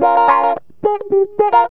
GTR 3 A#M110.wav